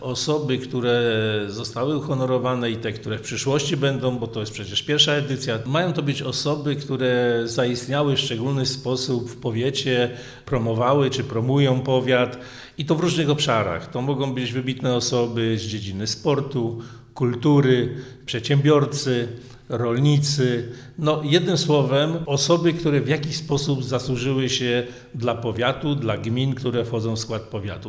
– Są to mieszkańcy, którzy promują nasz powiat – powiedział Waldemar Starosta, wschowski wicestarosta: